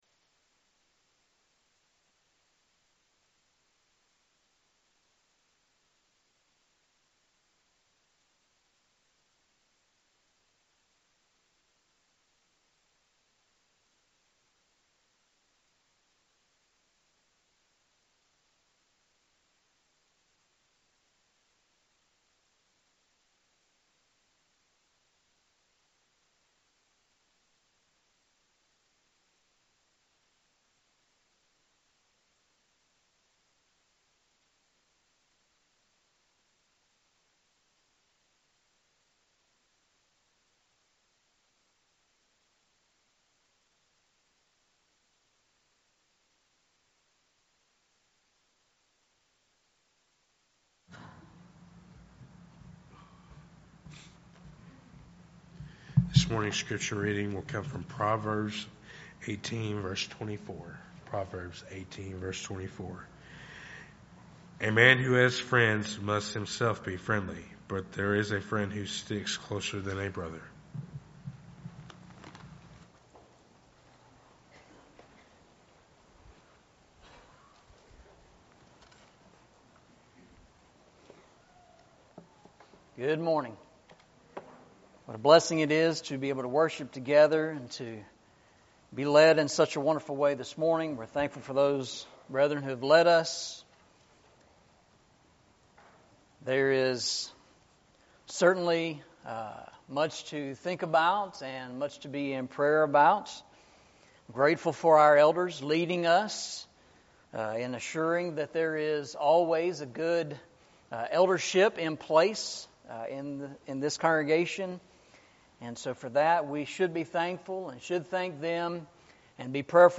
Eastside Sermons Passage: Proverbs 18:24 Service Type: Sunday Morning « Walking Through the Bible